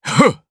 DarkKasel-Vox_Attack1_jp.wav